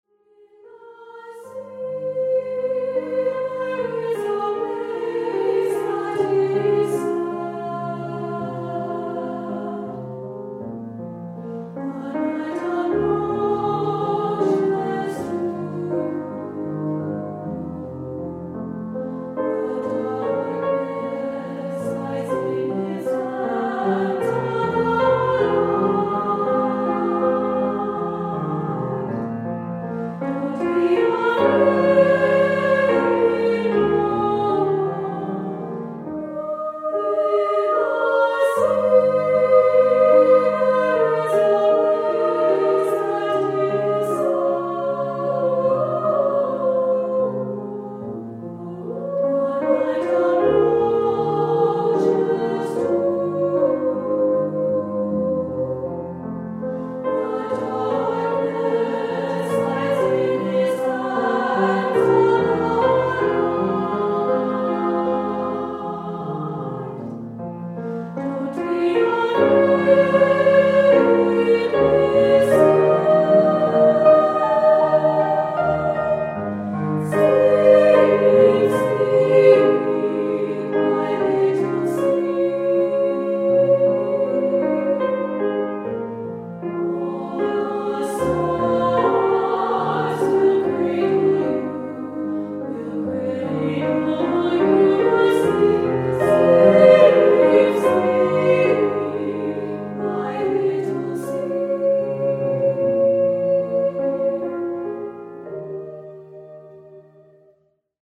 Choeur SA et Piano